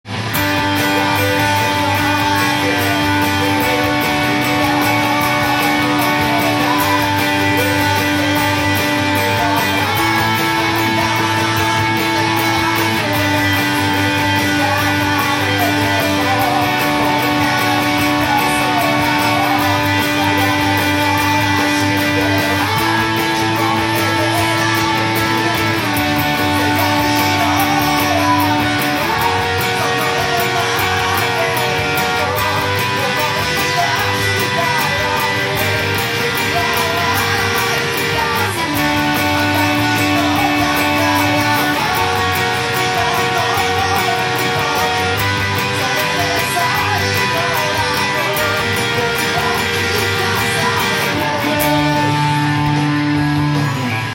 音源に合わせて譜面通り弾いてみました
譜面の方は主に２音の和音を弾いていきます。
かなりのハイテンポなのでBPM１５０です。